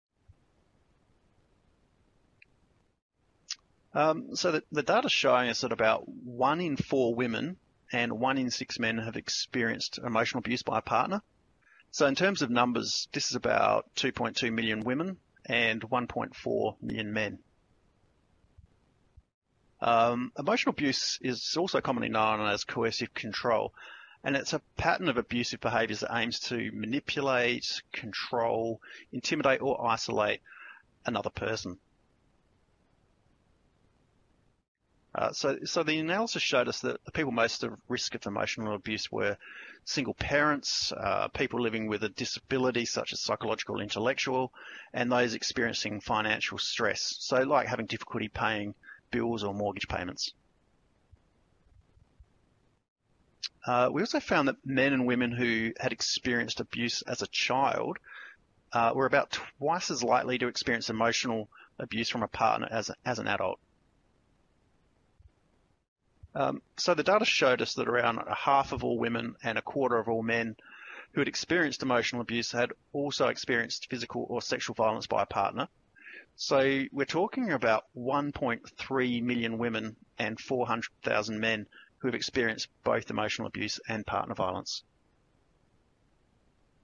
Domestic Violence - Experiences of Partner Emotional Abuse, Audio Grabs
Domestic Violence - Experiences of Partner Emotional Abuse, Audio Grabs.mp3